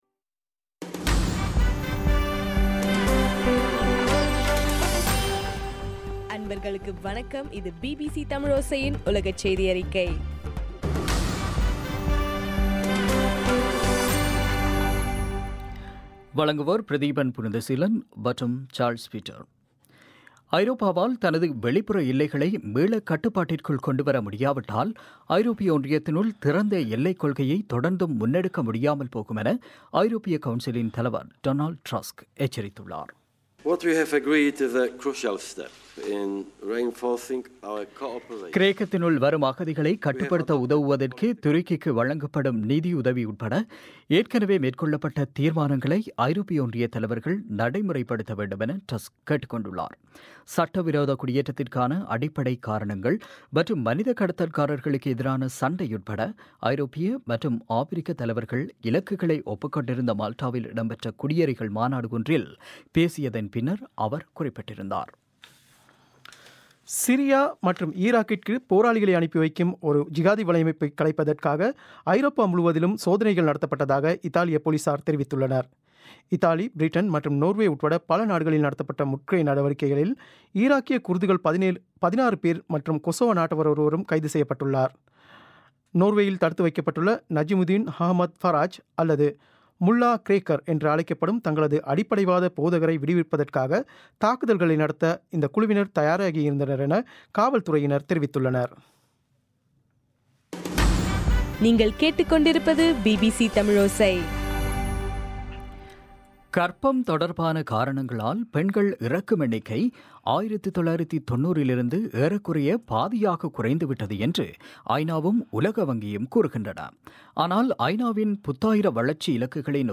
நவம்பர் 12 பிபிசியின் உலகச் செய்திகள்